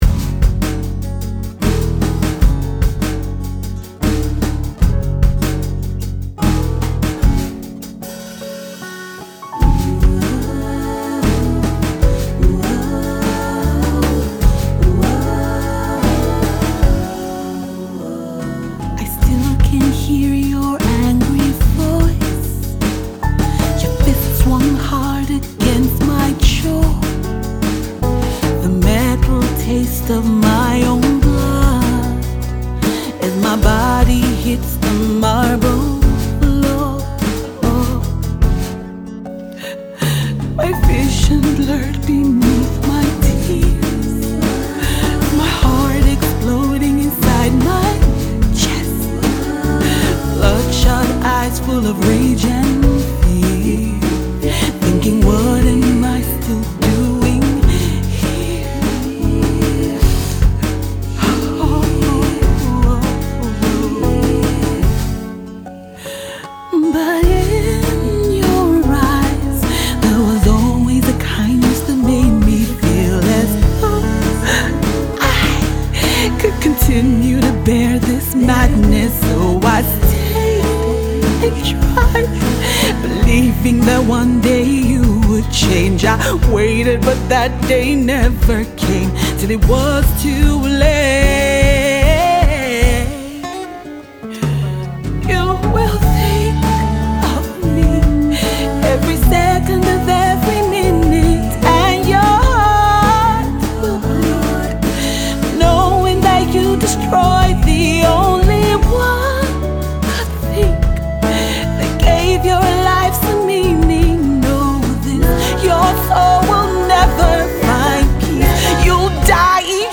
not many tell a story better than this Afro Soul Diva